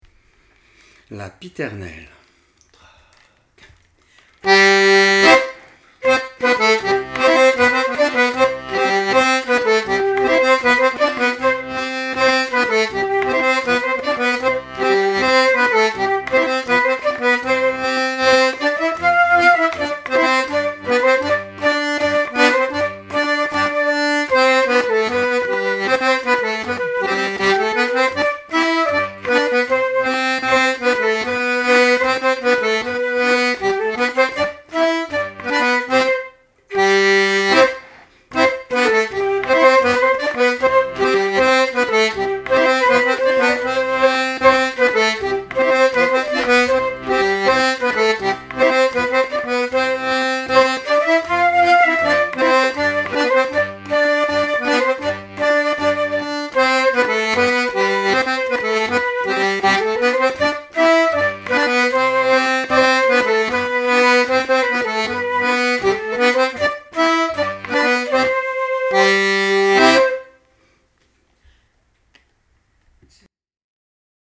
4 nouveaux morceaux pour l'année : un nouveau rondeau qui vient remplacer notre las hemnas et la pieternelle qui vient remplacer la scottich des freres lambert (c'est une musique/danse du nord de France)  et Uskudara, un morceau turc pouvant se danser en scottich, et une tarentelle A Lu Mircatu.